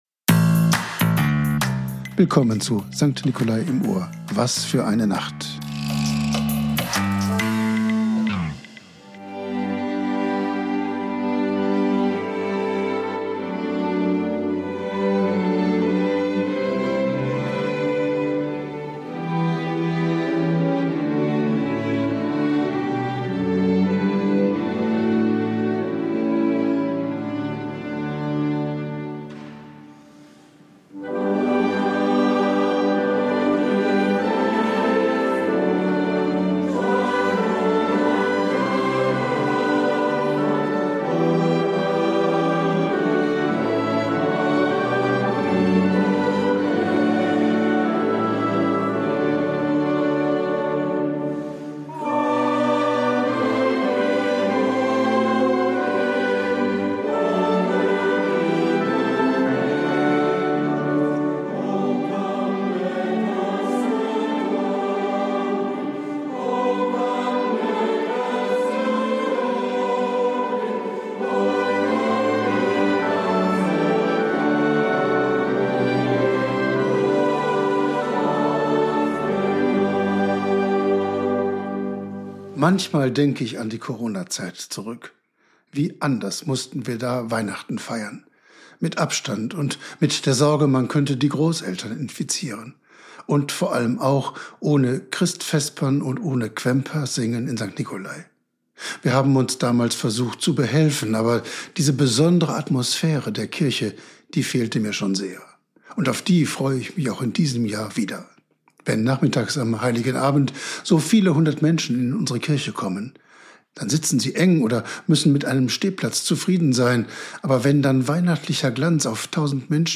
Wort und Musik aus der St. Nicolai-Kirche Lemgo